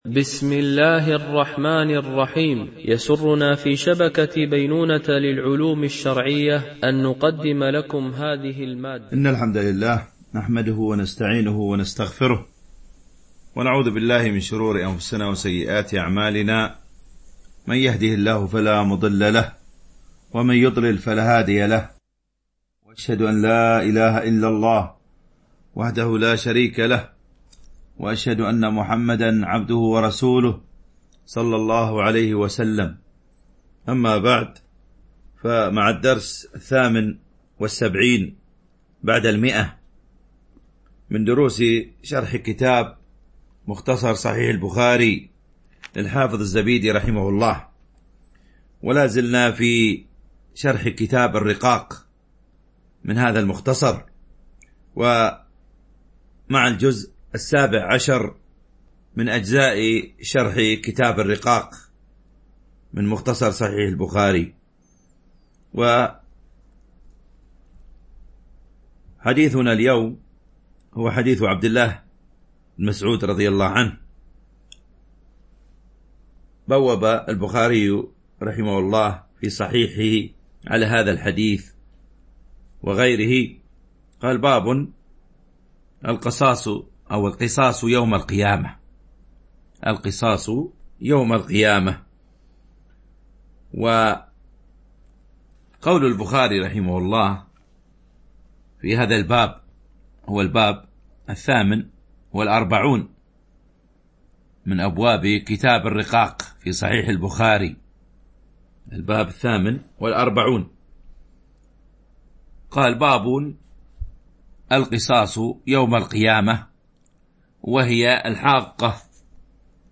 شرح مختصر صحيح البخاري ـ الدرس 178 ( كتاب الرقاق ـ الجزء السابع عشر - الحديث 2125 )